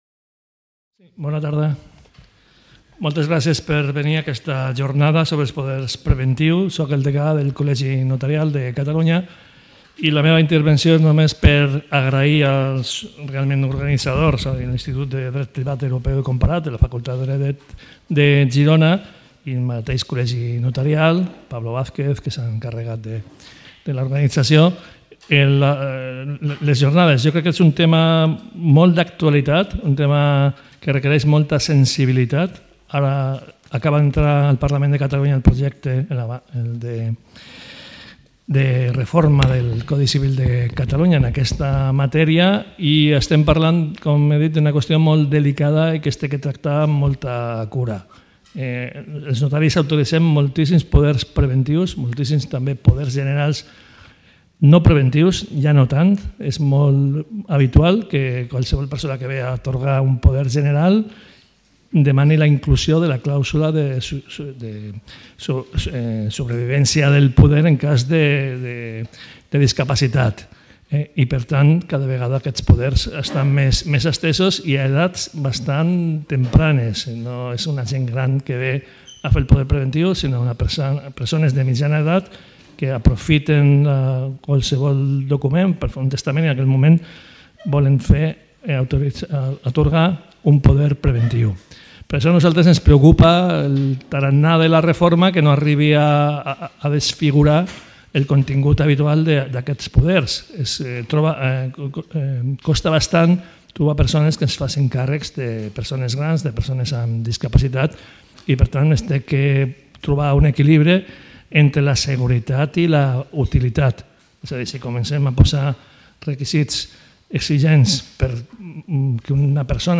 Els poders preventius a la teoria i a la pràctica. Jornada tècnica. Presentació i benvinguda